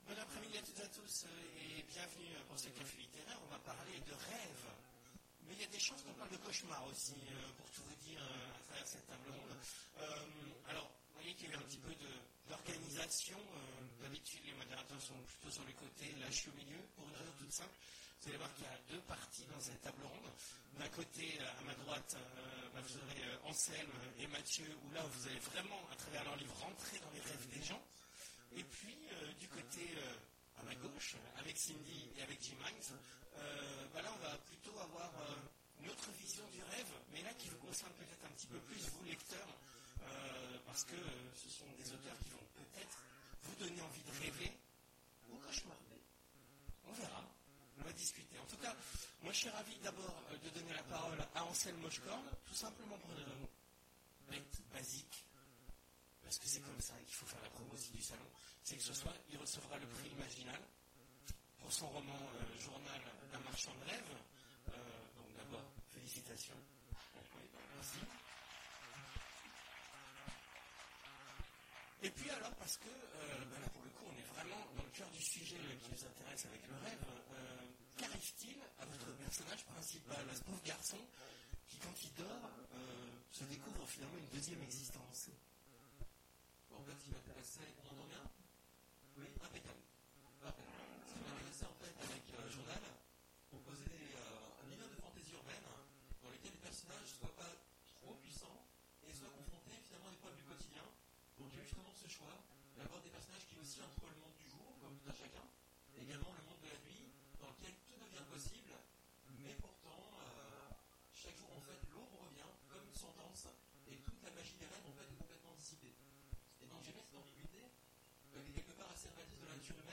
Imaginales 2017 : Conférence Ils viendront vous traquer… Jusque dans vos rêves !